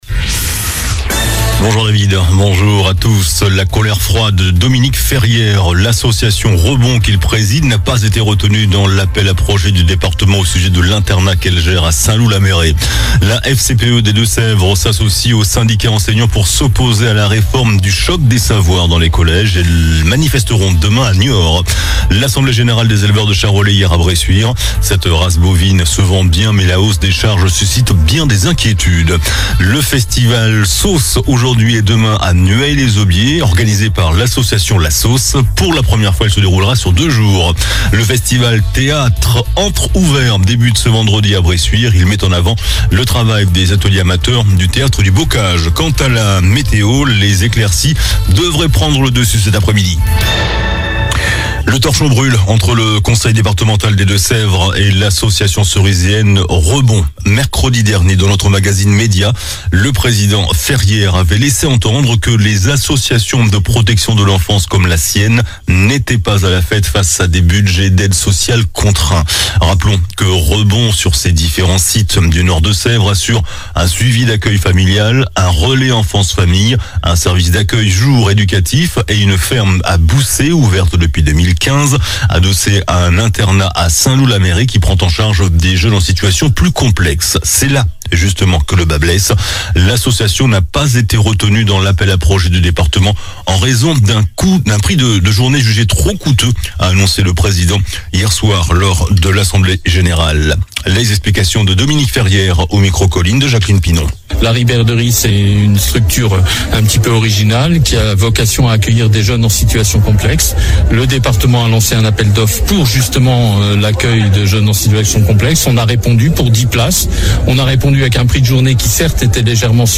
JOURNAL DU VENDREDI 24 MAI ( MIDI )